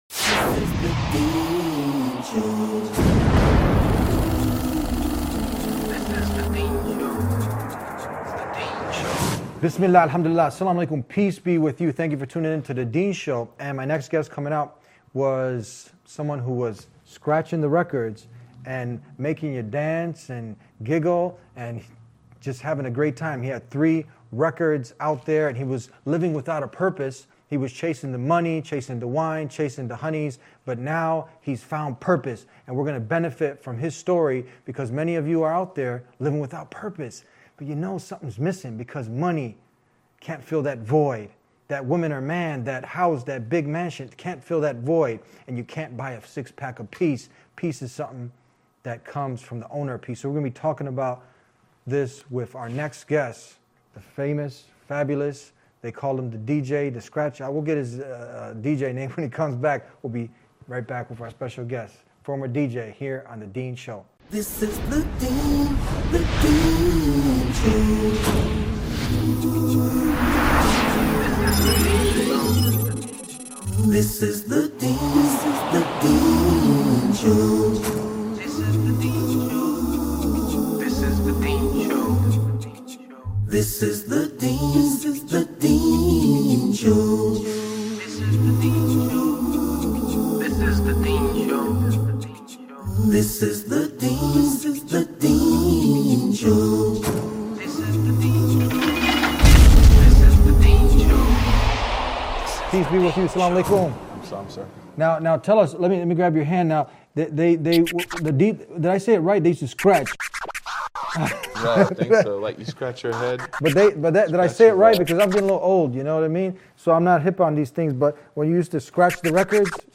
An amazing episode with a Former DJ about how he found peace and happiness in life after he found the true purpose of life.